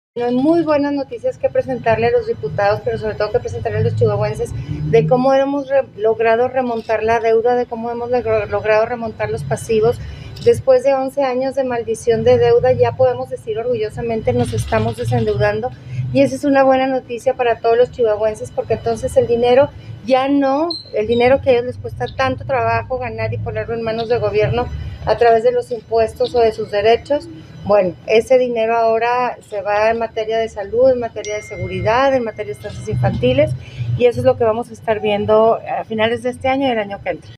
AUDIO: MARÍA EUGENIA CAMPOS, GOBERNADORA DEL ESTADO DE CHIHUAHUA